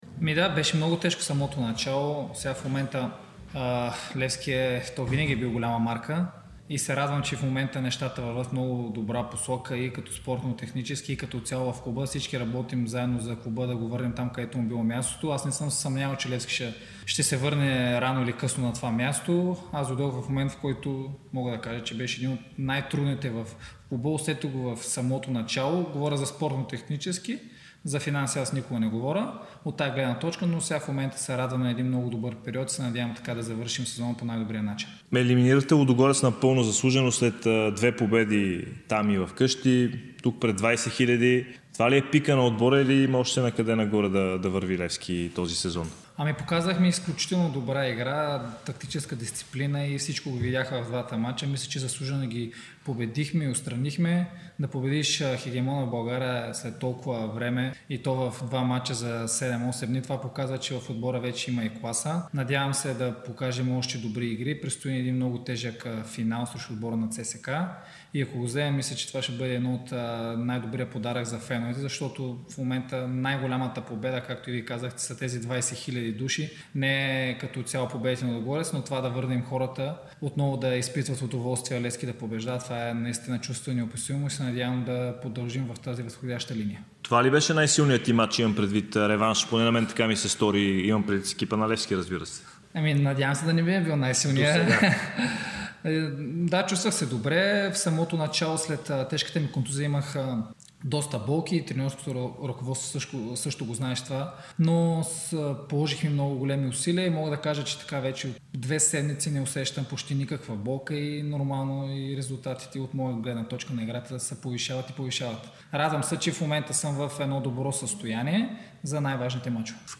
Полузащитникът на Левски Георги Миланов даде ексклузивно интервю пред dsport и Дарик радио. Той сподели очакванията си „сините“ да спечелят финала за Sesame Купа на България срещу ЦСКА.